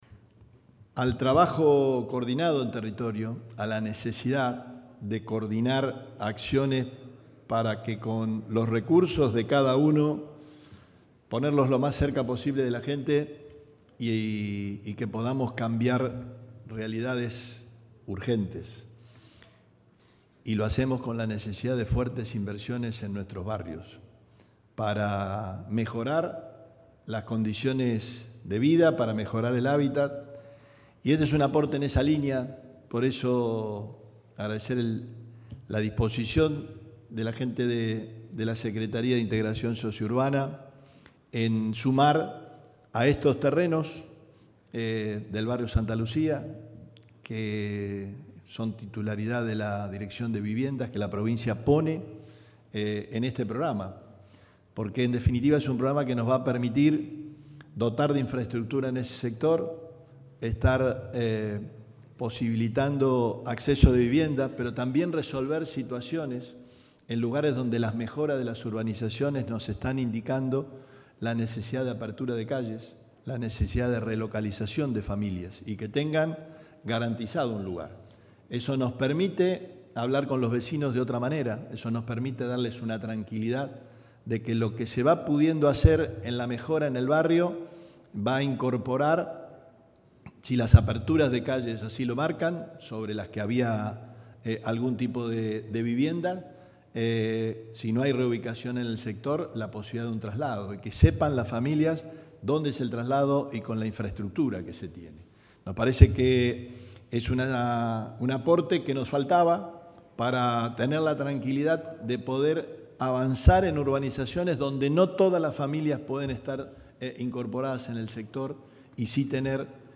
Declaraciones Perotti